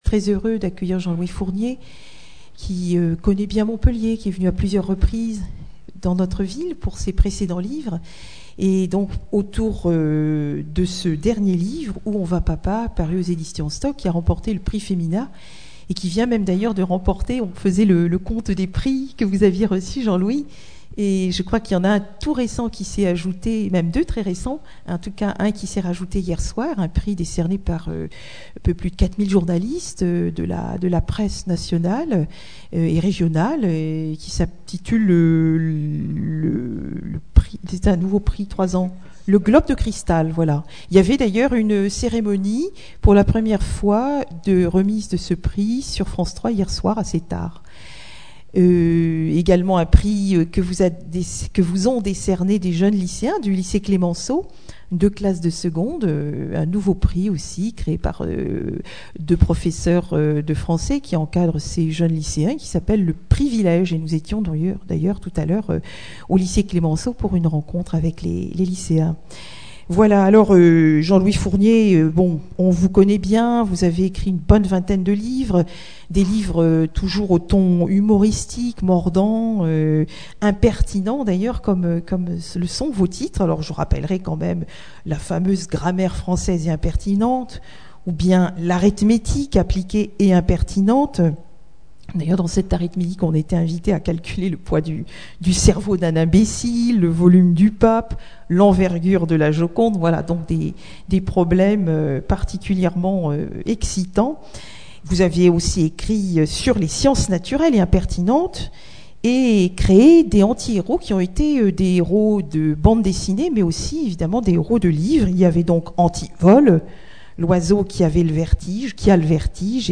Fournier, Jean-Louis. Personne interviewée
Rencontre littéraire